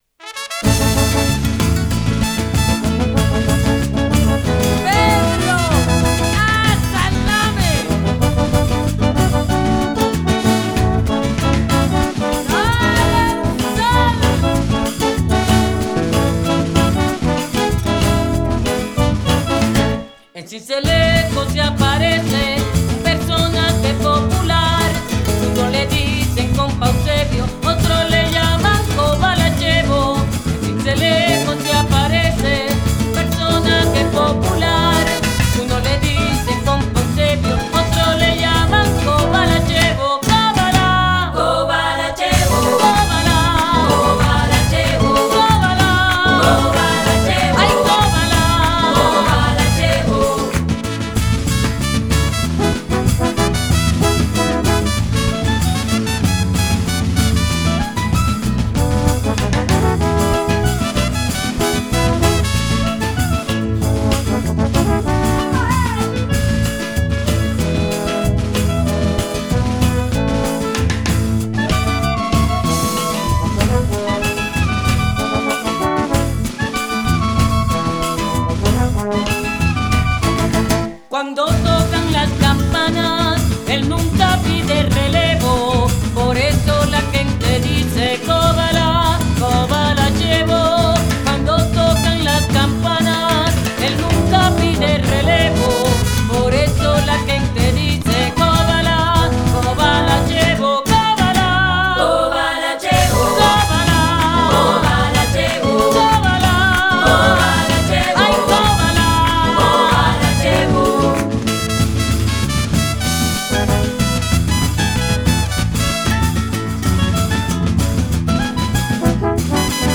“CÓBALACHEBO”  (Porro sabanero)